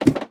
ladder3.ogg